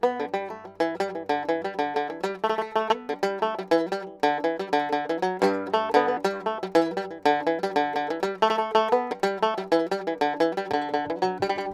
Banjo
banjo.mp3